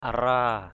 /a-ra:/ (d.) chim le le = sarcelle. teal (a small freshwater duck, typically with a greenish band on the wing that is most prominent in flight).